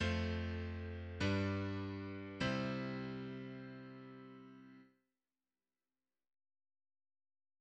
Последовательность IV–V–I в тональности до мажор. Представлены аккорды фа мажор, соль мажор и до мажор.